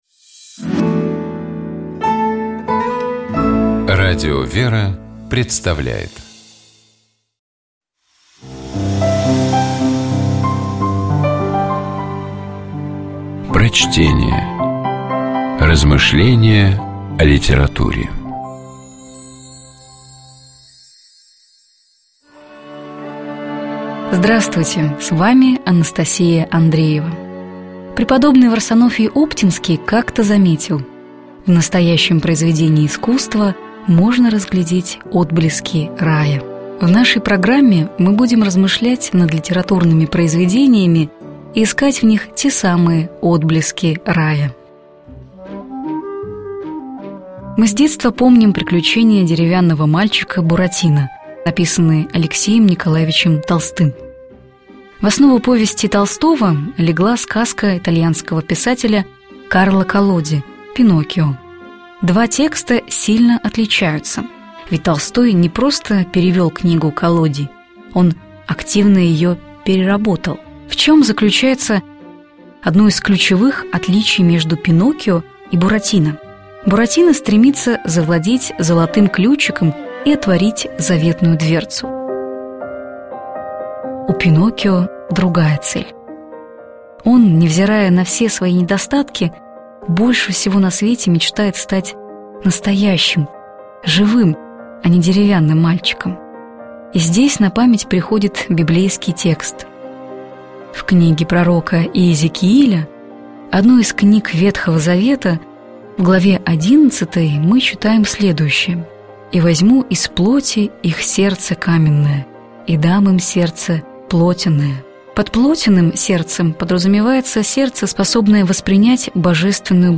Prochtenie-Karlo-Kollodi-Pinokkio-Stat-nastojashhim.mp3